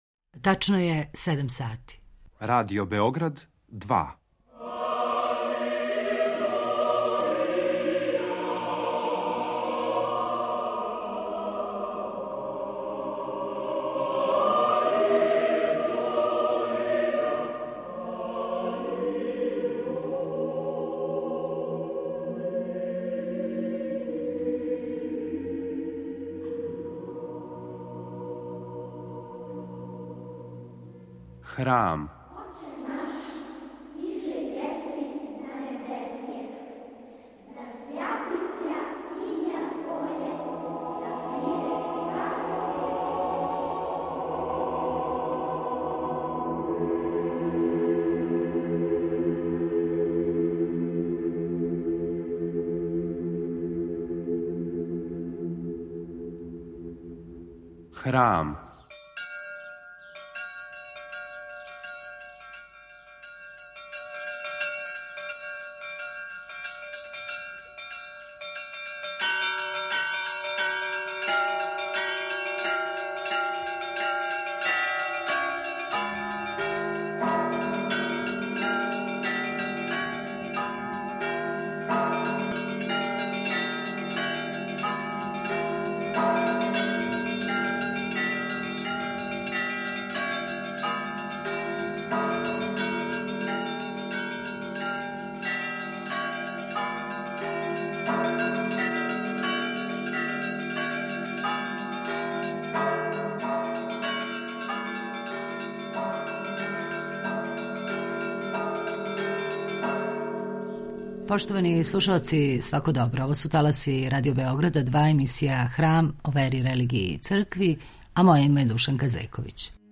Емисија о вери, религији, цркви...